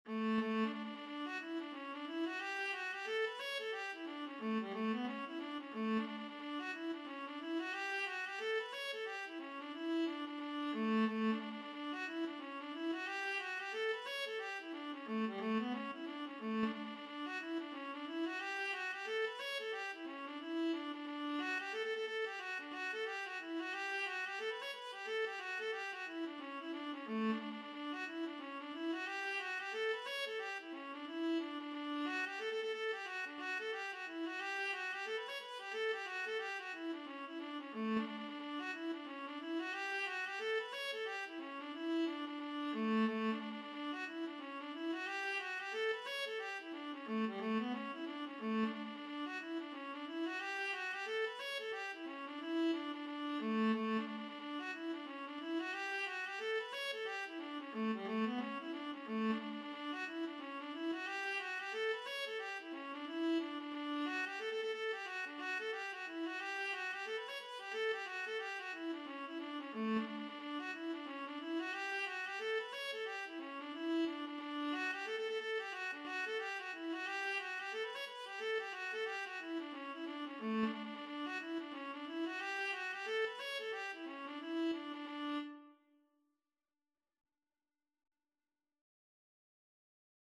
Traditional Trad. Early in the Morning (Irish Folk Song) Viola version
4/4 (View more 4/4 Music)
D major (Sounding Pitch) (View more D major Music for Viola )
Viola  (View more Intermediate Viola Music)
Traditional (View more Traditional Viola Music)
Irish